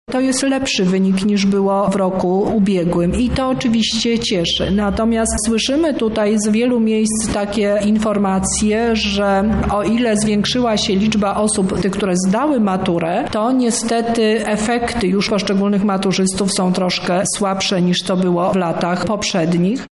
– komentuje Teresa Misiuk, Lubelski Kurator Oświaty.